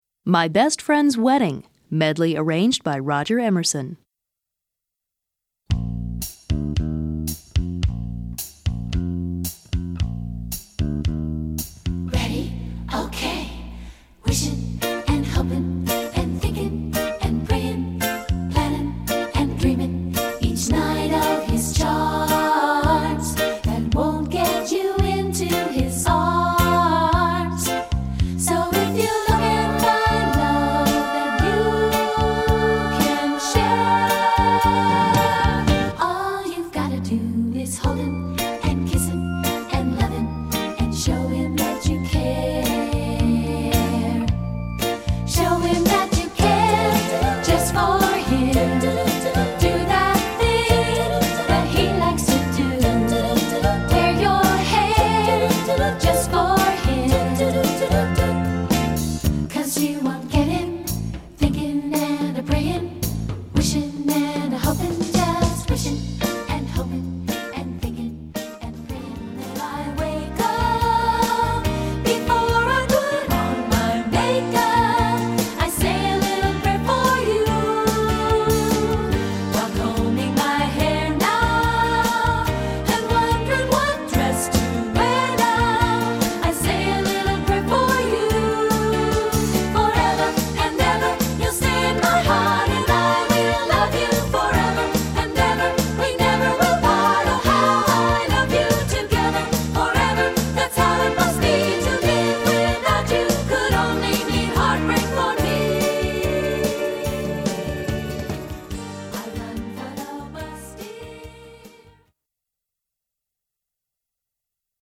Choeur (Unisson)